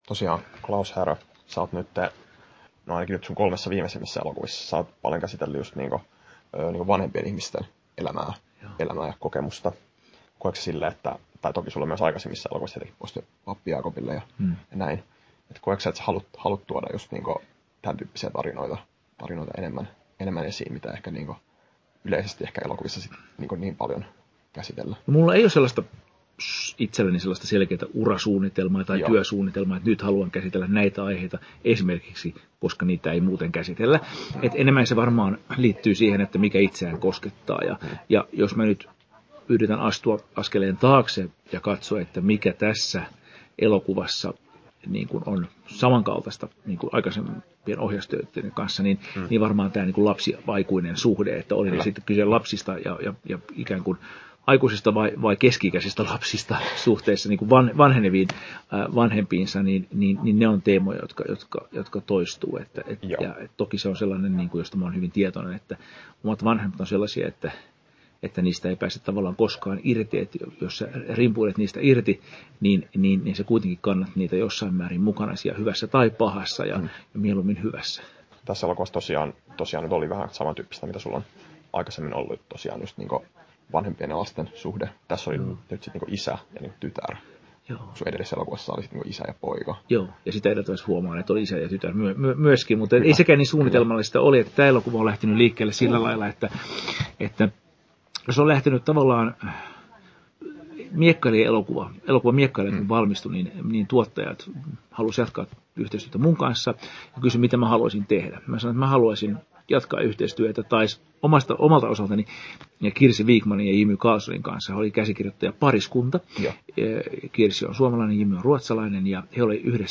Haastattelussa Klaus Härö Kesto: 19'21" Tallennettu: 19.9.2022, Turku Toimittaja